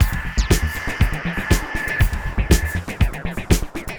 Code Red (Full) 120BPM.wav